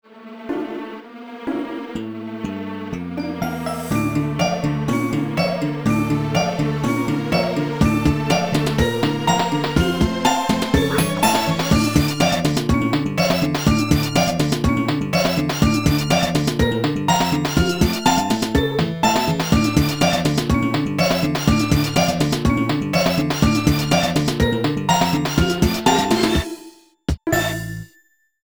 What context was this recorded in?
This file is an audio rip from a(n) Nintendo DS game.